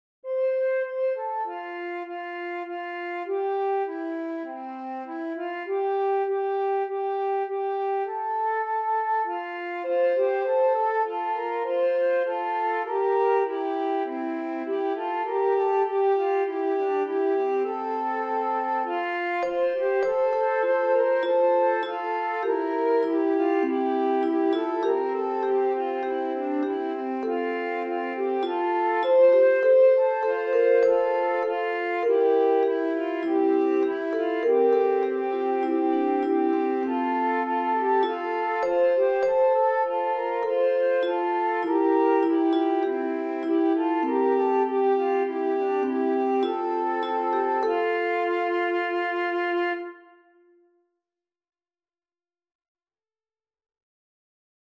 Een canon voor de zomer
Driestemmig